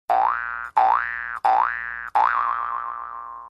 toink
toink-sound-effect-gg-green-screens-youtube-online-audio-converter.mp3